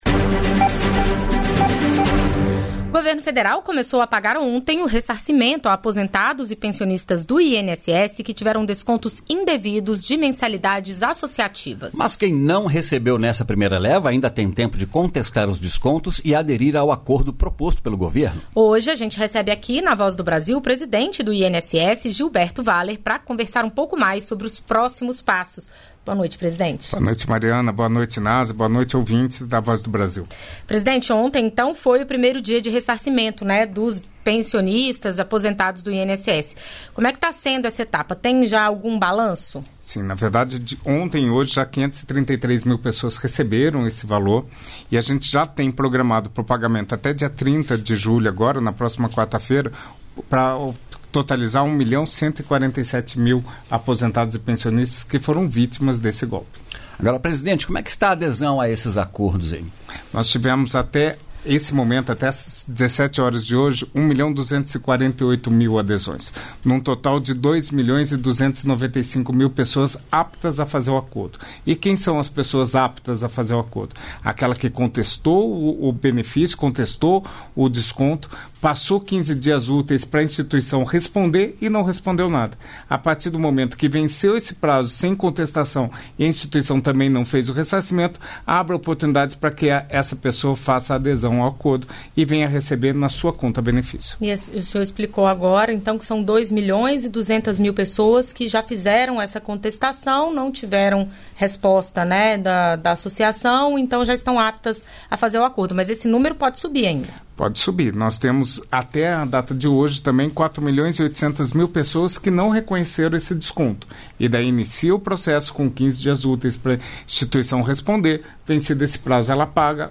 Entrevistas da Voz
Na Operação Sussuarana, ICMbio executa retirada de 400 cabeças de gado ilegais dentro da Reserva Extrativista Chico Mendes. Esse é o tema da entrevista com Mauro Pires, que também fala sobre os tipos de ilegalidades encontradas pela fiscalização.